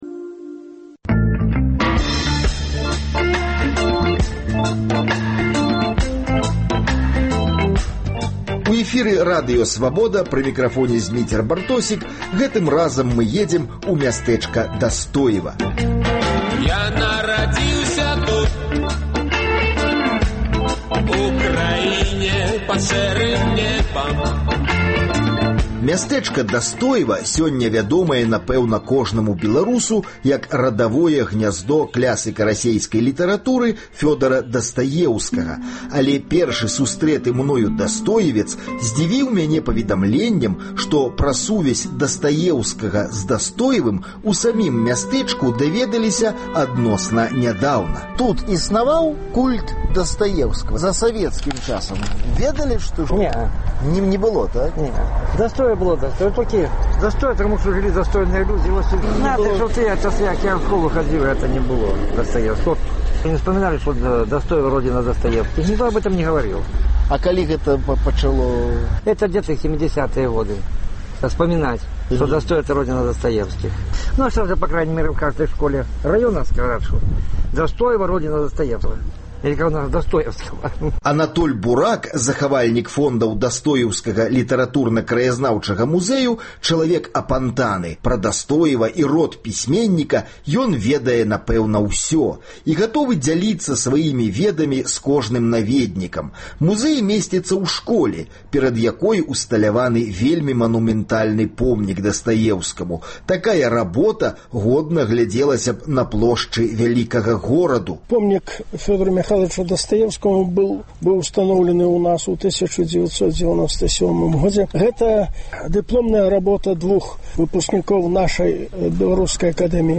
Паездкі нашых карэспандэнтаў па гарадах і вёсках Беларусі. Ці ганарацца жыхары Дастоева пісменьнікам Дастаеўскім?